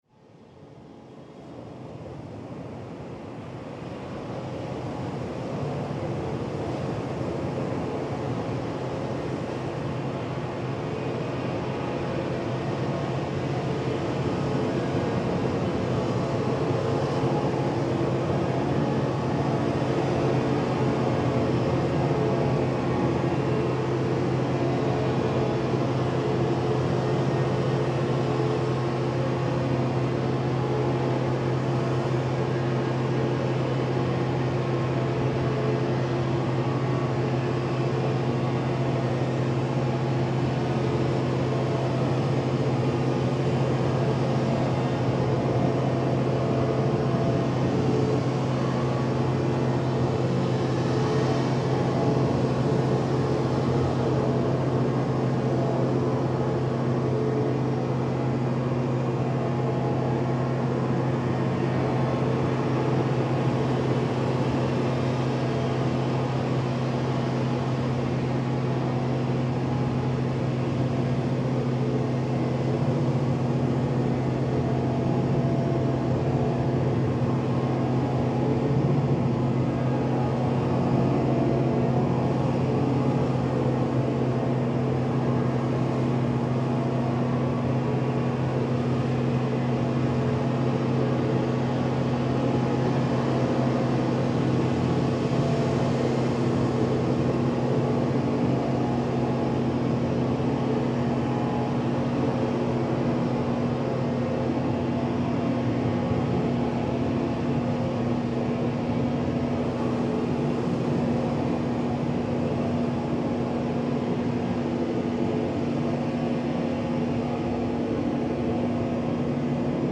Mahdia sea at night